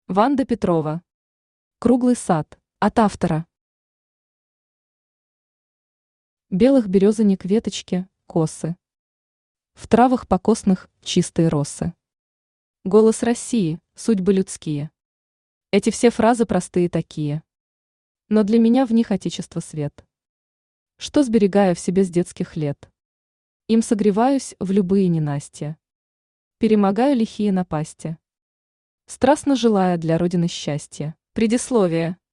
Аудиокнига Круглый сад | Библиотека аудиокниг
Aудиокнига Круглый сад Автор Ванда Михайловна Петрова Читает аудиокнигу Авточтец ЛитРес.